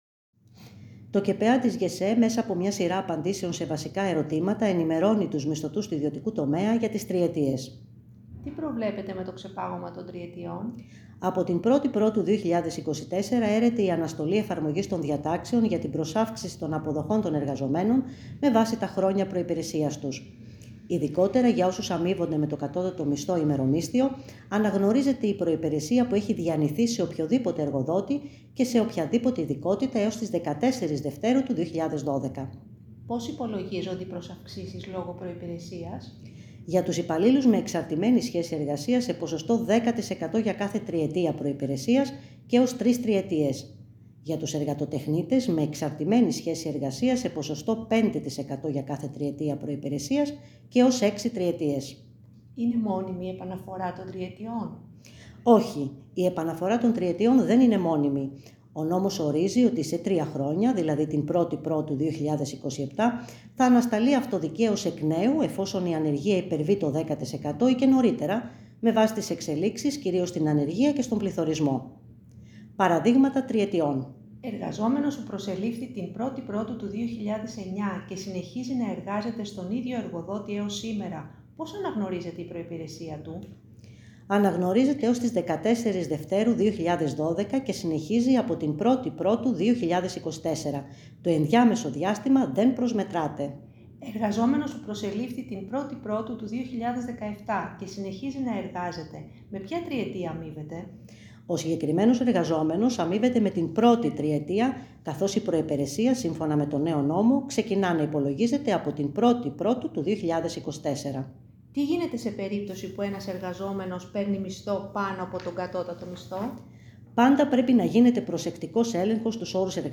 ΓΣΕΕ – ΚΕΠΕΑ: Σύντομες ηχητικές ερωτοαπαντήσεις για τις τριετίες και αναλυτικοί πίνακες